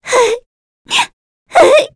Pansirone-Vox_Sad_kr.wav